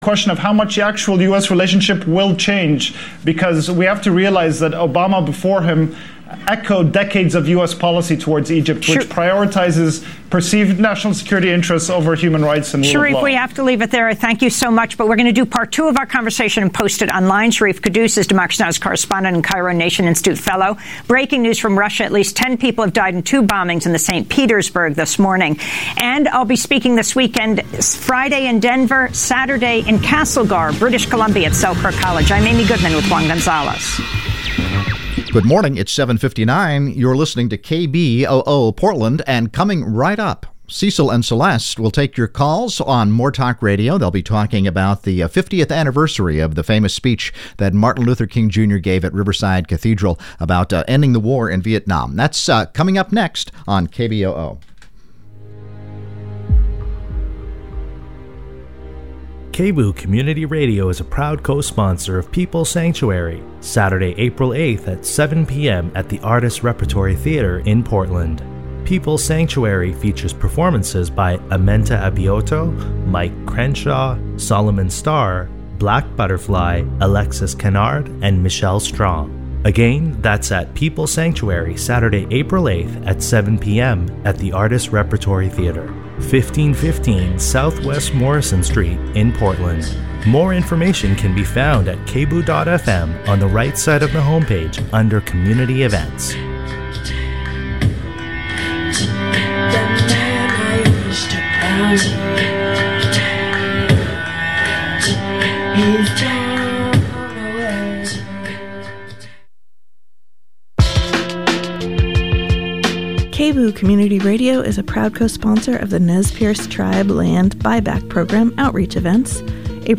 Download audio file Two women with very close ties to Martin Luther King Jr. reflect on how King developed into one of the great moral and political philosophers of the 20th century and how his philosophies might still guide the world through troubled times today.